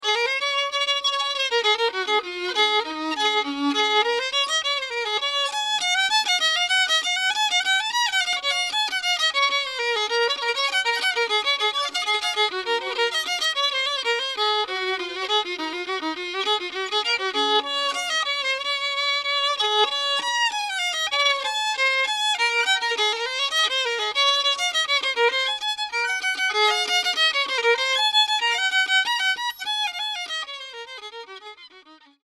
Performs mainly traditional songs and tunes on acoustic fiddle, mandolin and guitar.
Takes material from Irish, Scottish and American sources.